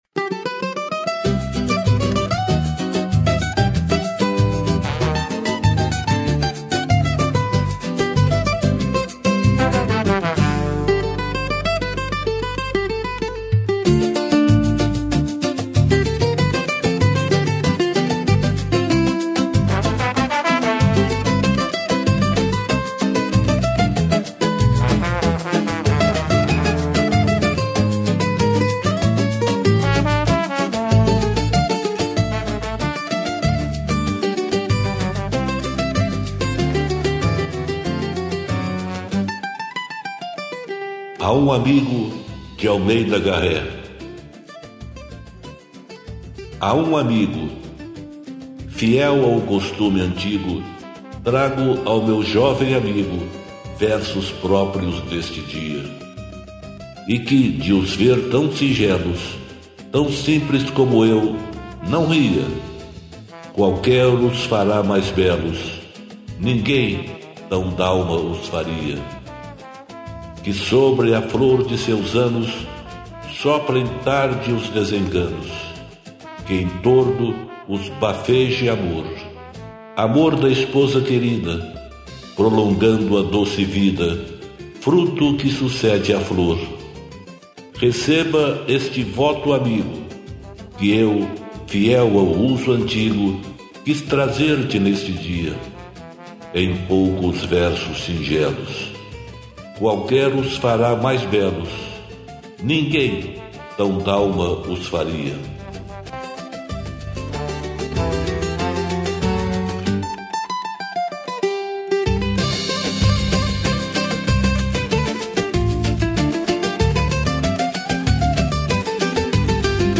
musica: IA